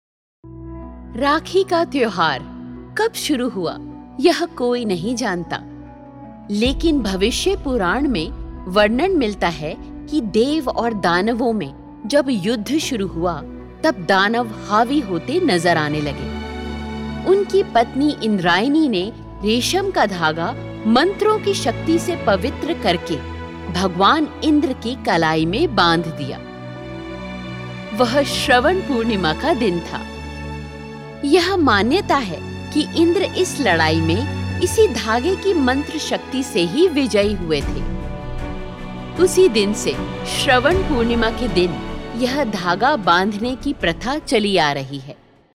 Vídeos Explicativos
Equipamento de estúdio em casa
Mic: Shure SM58
AltoSoprano